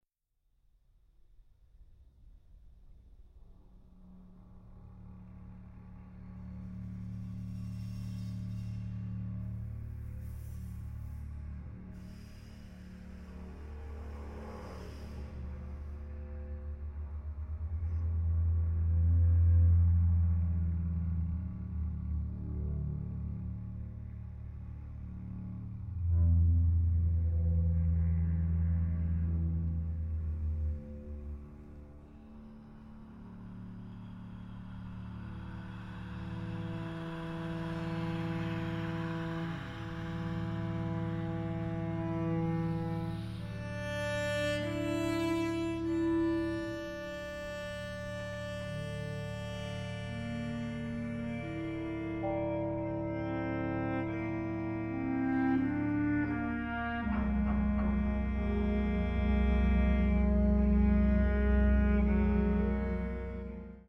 • Genres: Classical, Vocal